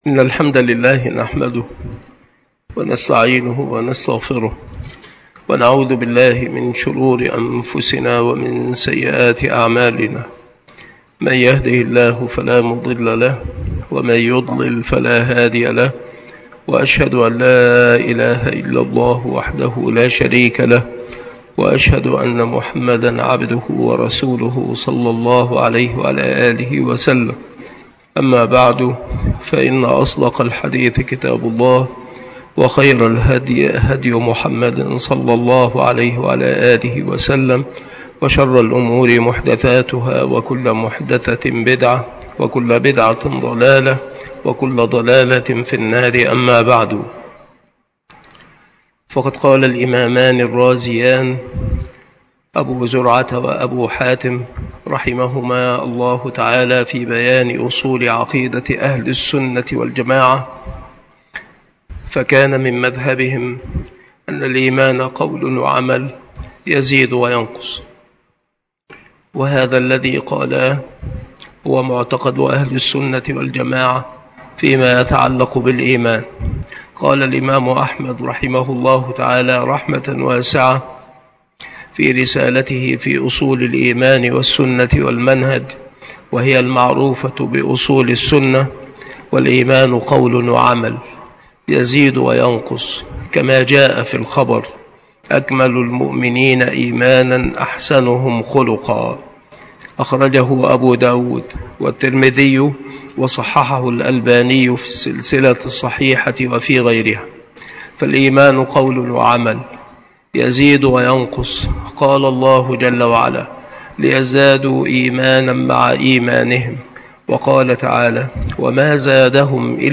مكان إلقاء هذه المحاضرة بالمسجد الشرقي بسبك الأحد - أشمون - محافظة المنوفية - مصر عناصر المحاضرة : عقيدة أهل السنة في الإيمان.